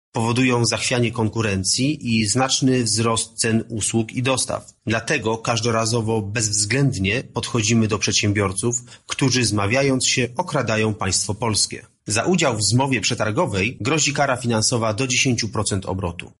Zmowy przetargowe bardzo poważnie uszczuplają budżet państwa – mówi wiceprezes Urzędu Ochrony Konkurencji i Konsumentów Michał Holeksa: